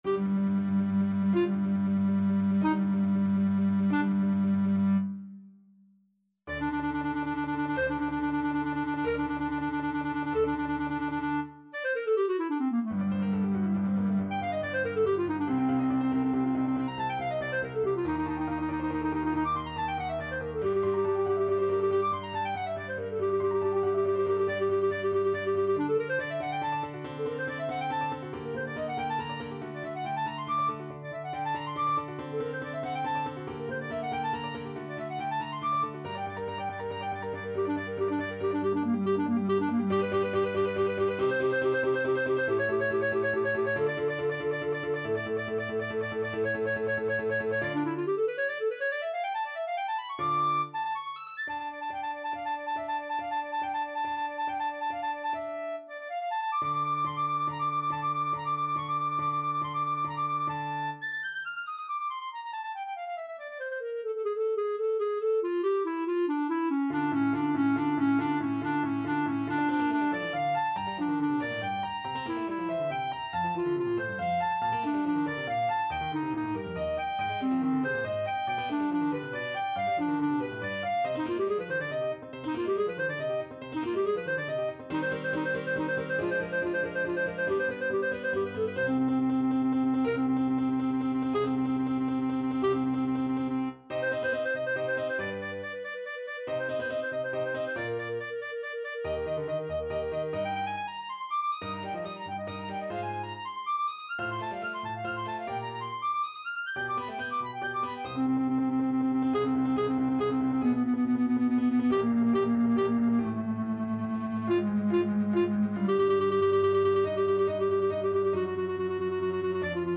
~ = 140 Presto (View more music marked Presto)
3/4 (View more 3/4 Music)
Clarinet  (View more Advanced Clarinet Music)
Classical (View more Classical Clarinet Music)